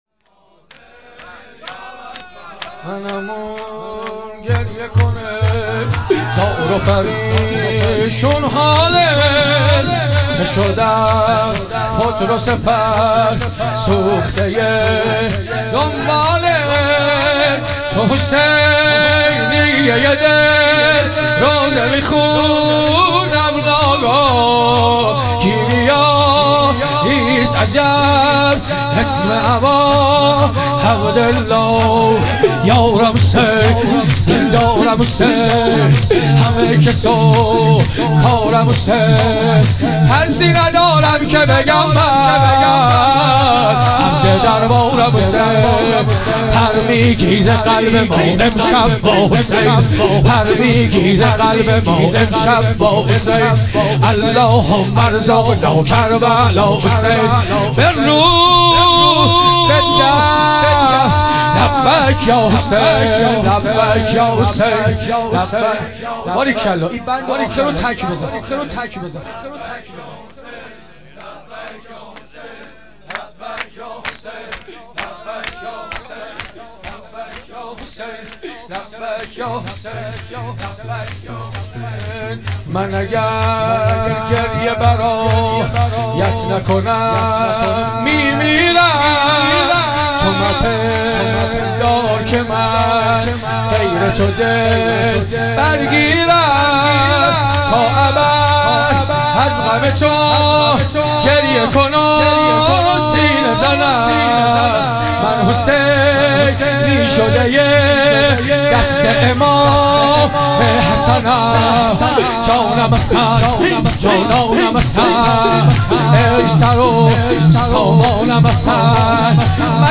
دهه دوم محرم ۹۸.شب دوم